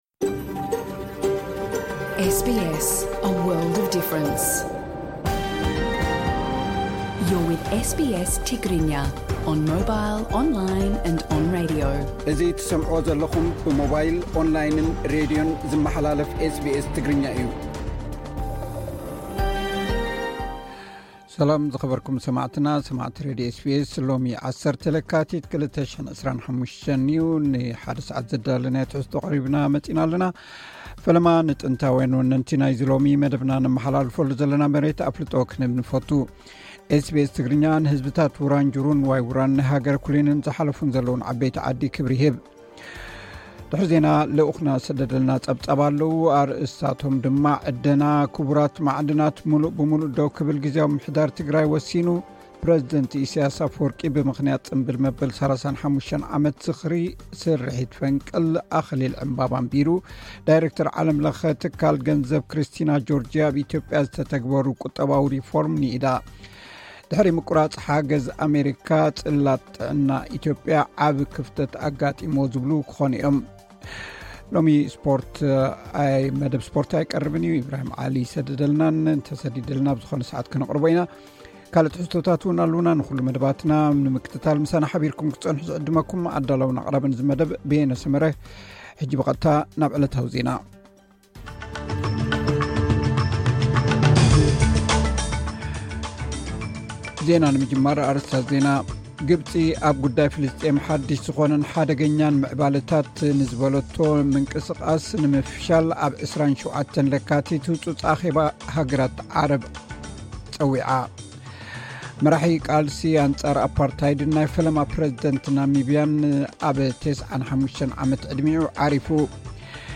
ዕለታዊ ዜና ኤስ ቢ ኤስ ትግርኛ (10 ለካቲት 2025)